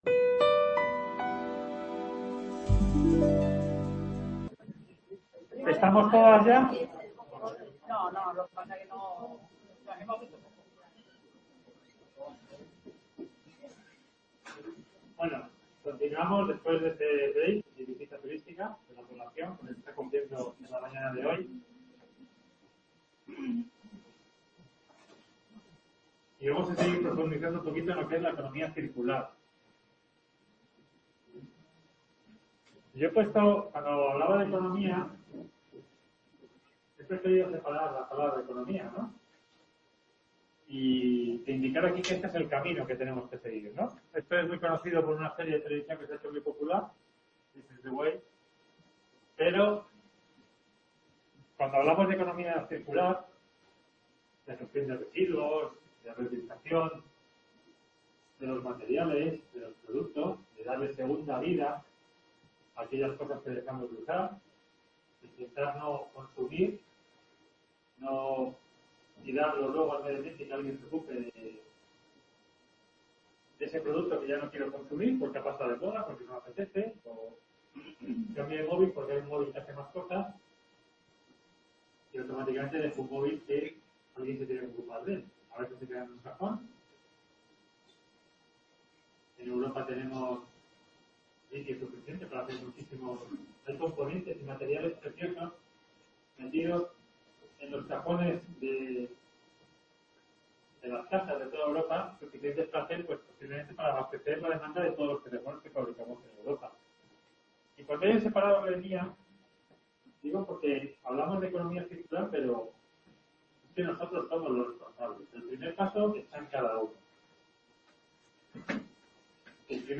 Jornadas dedicadas a la Economía Circular en el Mundo Rural, organizadas por la UCLM en colaboración con la UNED de Talavera de la Reina. Un espacio abierto de diálogo y propuestas para el desarrollo sostenible de las zonas rurales.